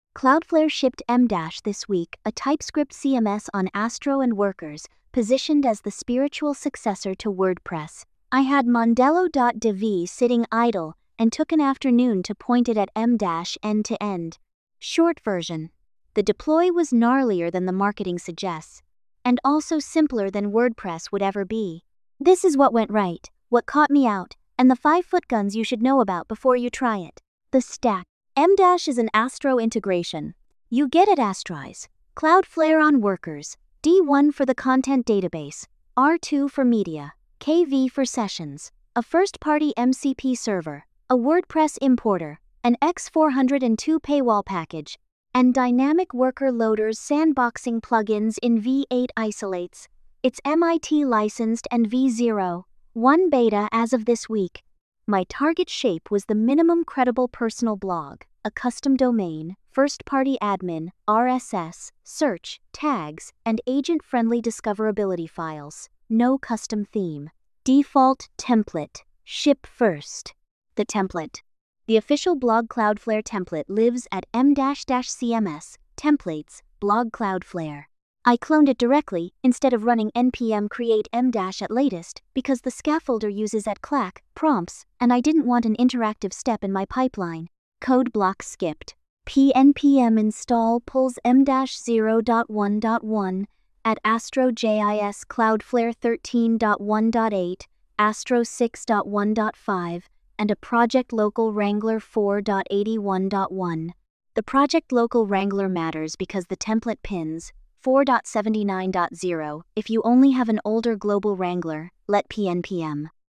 AI-narrated with MiniMax speech-2.8-hd · 1:53.